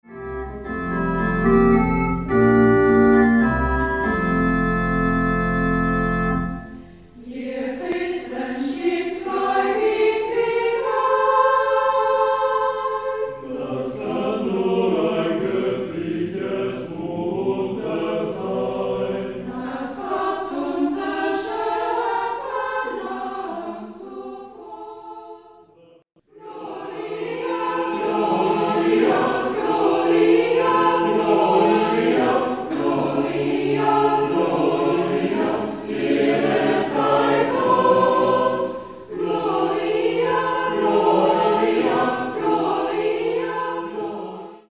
(Live-Aufnahmen)